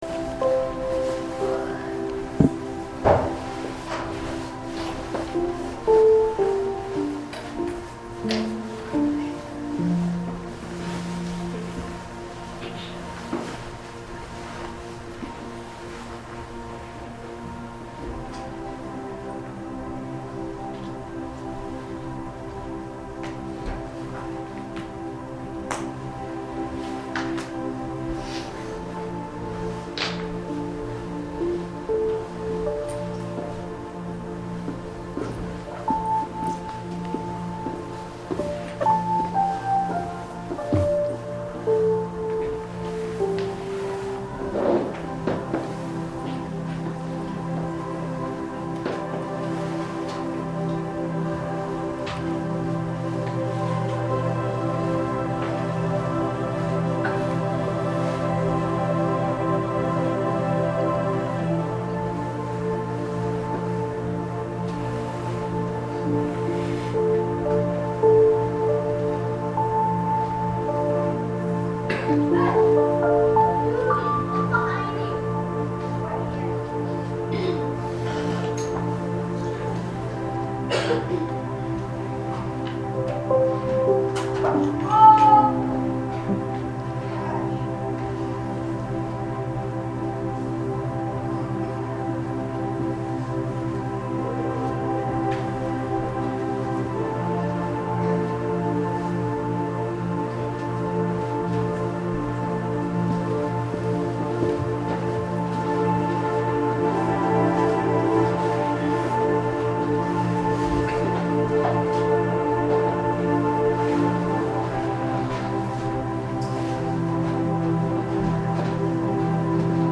正在播放：--2014年11月16日主日恩膏聚会（2014-11-16）